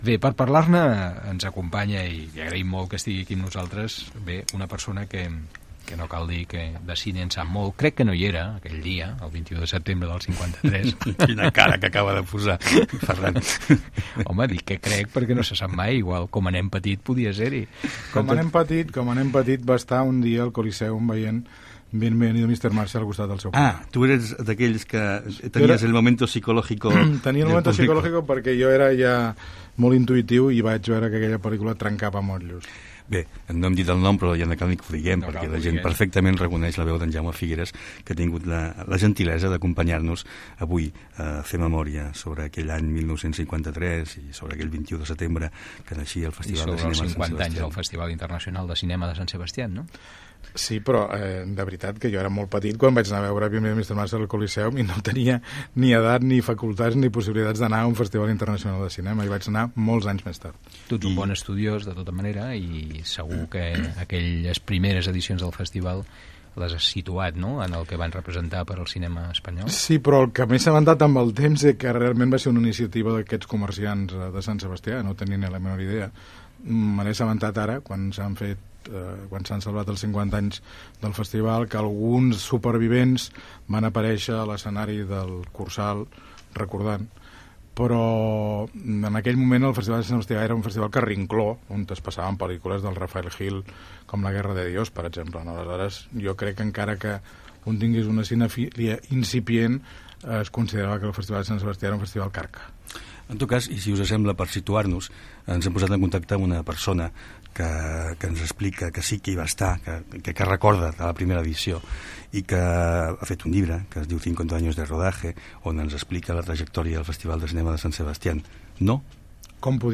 Conversa telefònica amb Diego Galán.
Entreteniment